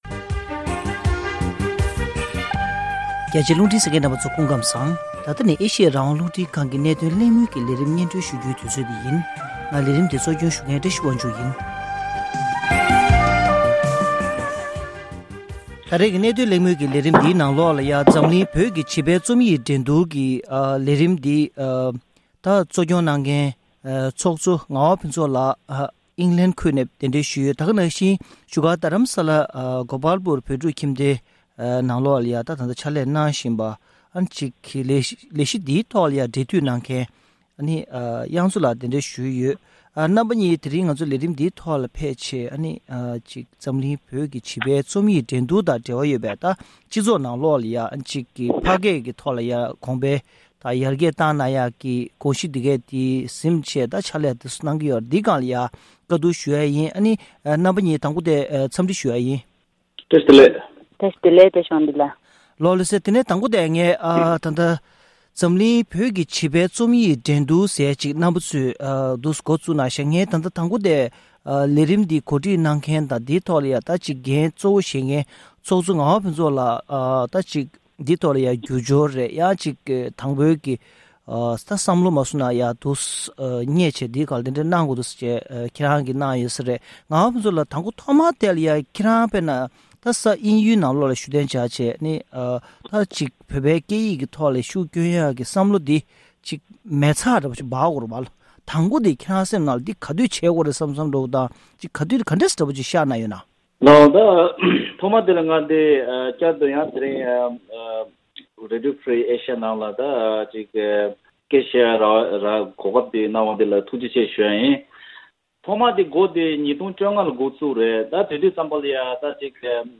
འཛམ་གླིང་བོད་ཀྱི་བྱིས་པའི་རྩོམ་ཡིག་དང་ཕ་སྐད་འགྲན་བསྡུར་གྱི་ལས་རིམ་སྐོར་འབྲེལ་ཡོད་ཁག་དང་གླེང་མོལ་ཞུས་པ།